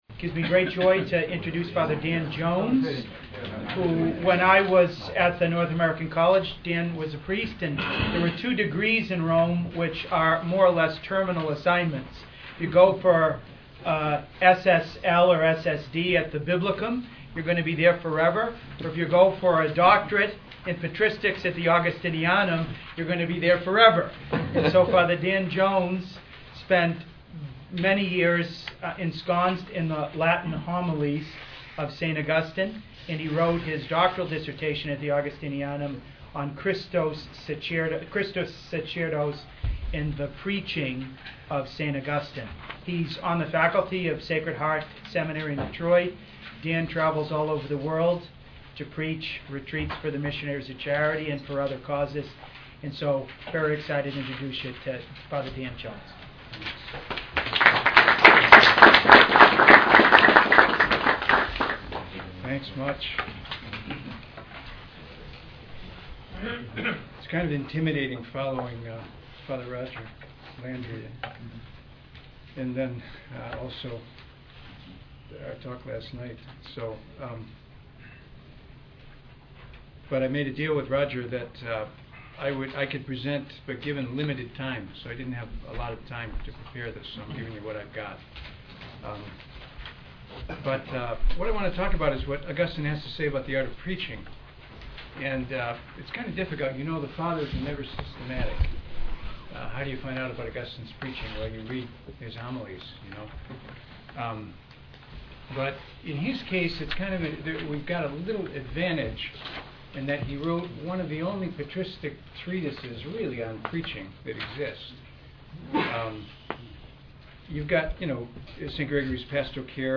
Talk on The Preaching of St. Augustine